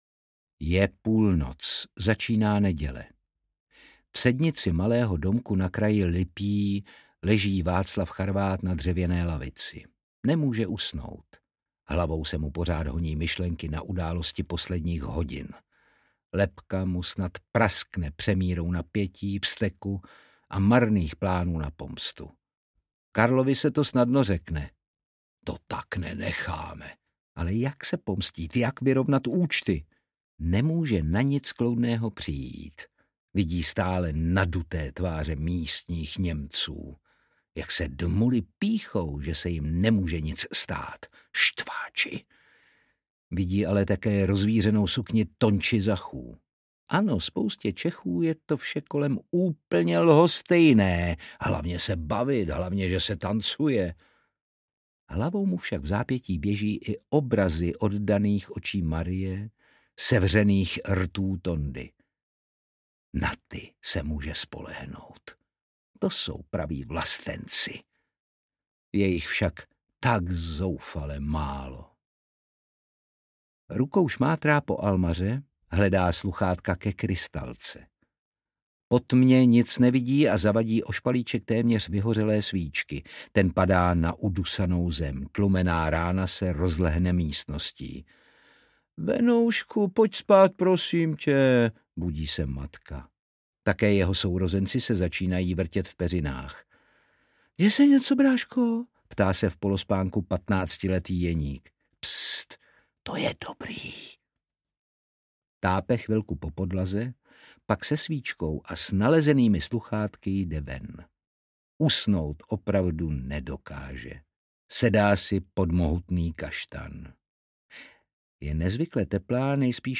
Kdo se směje naposled audiokniha
Ukázka z knihy
• InterpretJaroslava Brousková, Otakar Brousek